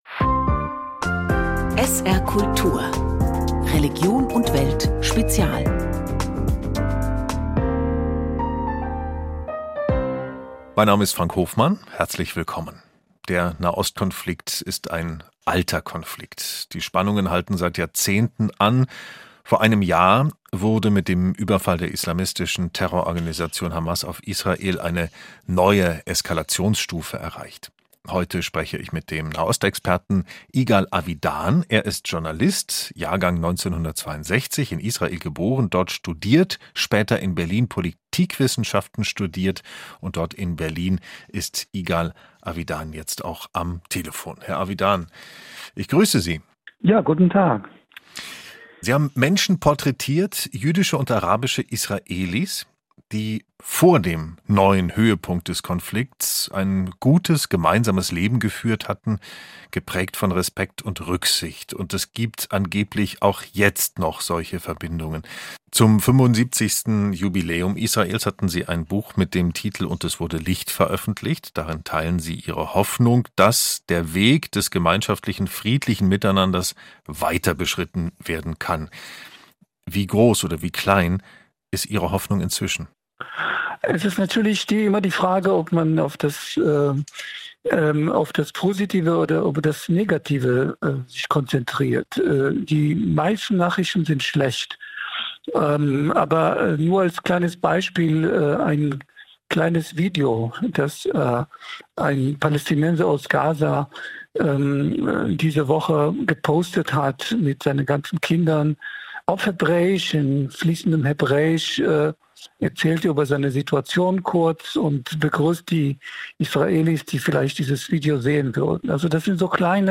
Sie hat die Öffentlichkeit im Prozess um ihre Vergewaltigungen zugelassen. Kann der Fall einen gesellschaftlichen Wandel bringen? Dazu die Journalisten
im Gespräch